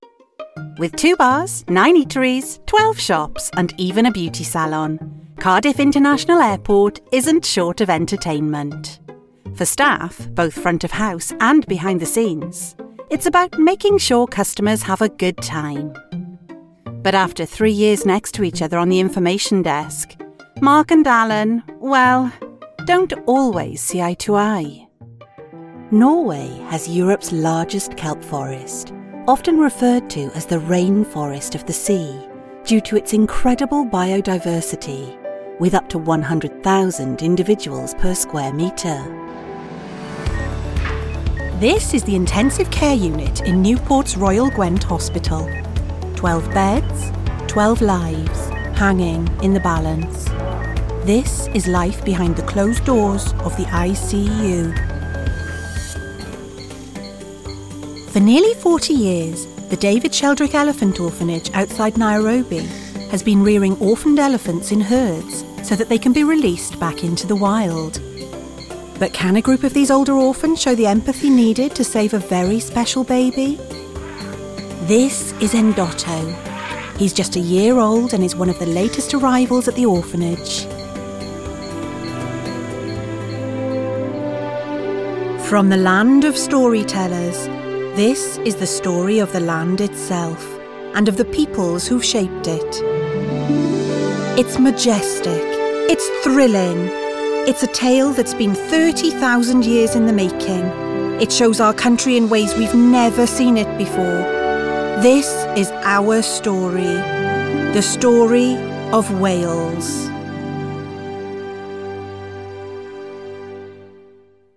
Documentary Showreel
Female
Reassuring